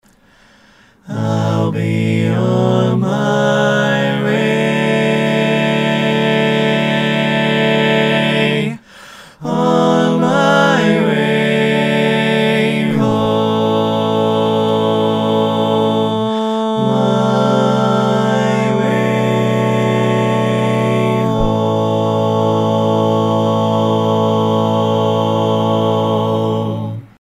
Key written in: A Major
How many parts: 4
Type: Barbershop
All Parts mix: